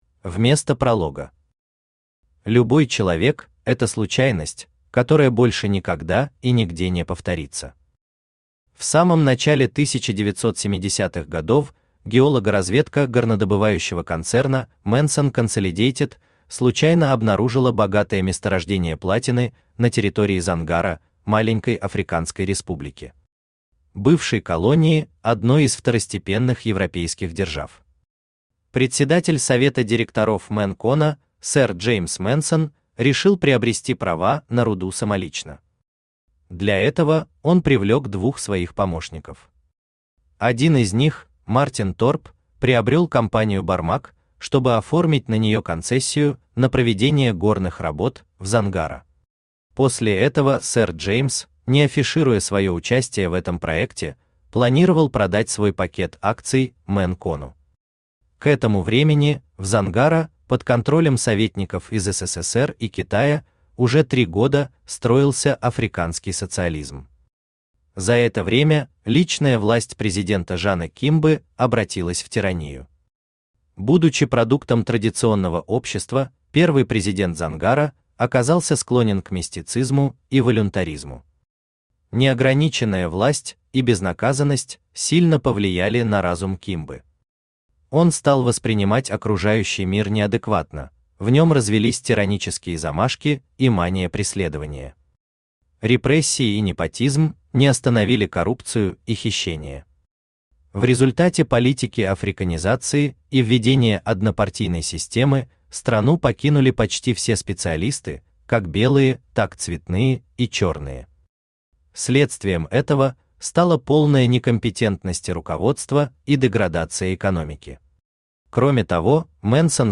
Аудиокнига Псы войны: дневники Шеннона | Библиотека аудиокниг
Aудиокнига Псы войны: дневники Шеннона Автор Олег Евгеньевич Пауллер Читает аудиокнигу Авточтец ЛитРес.